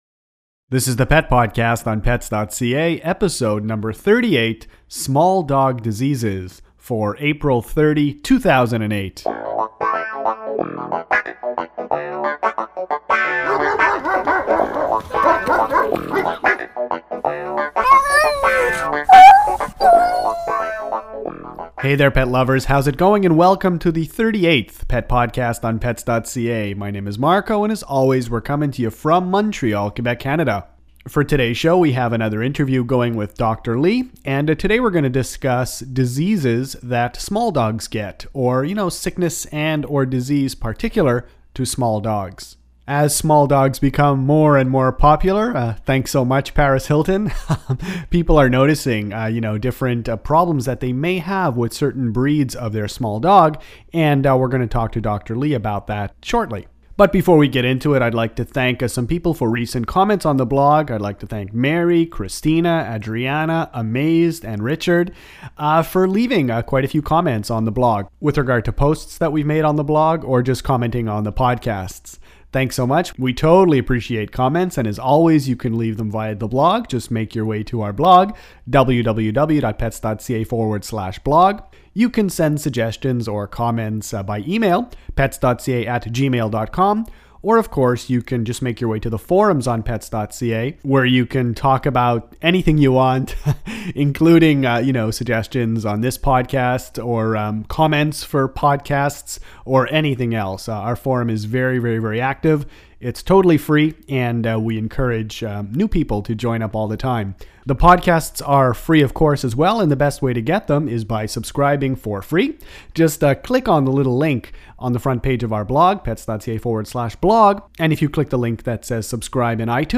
Small dog diseases – health problems – Pet podcast #38